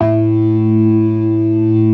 Index of /90_sSampleCDs/InVision Interactive - Keith Emerson Lucky Man/Partition F/ORGAN+SYNTH4